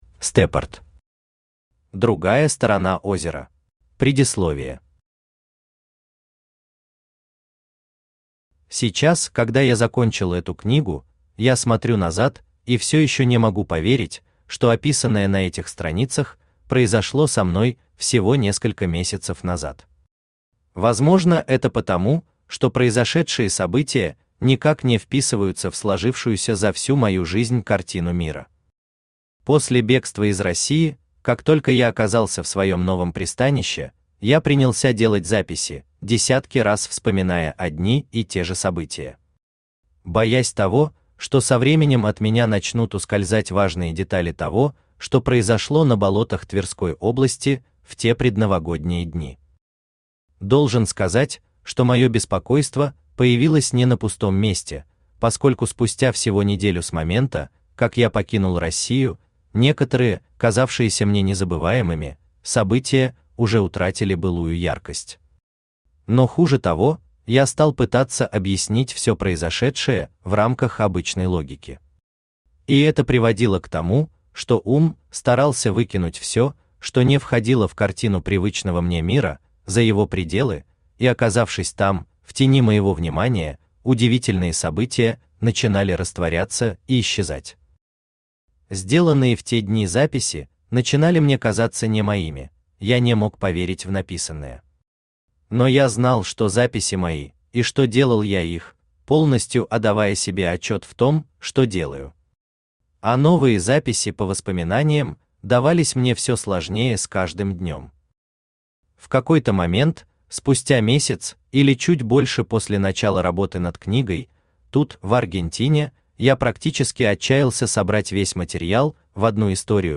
Аудиокнига Другая сторона озера | Библиотека аудиокниг
Aудиокнига Другая сторона озера Автор Stepart Читает аудиокнигу Авточтец ЛитРес.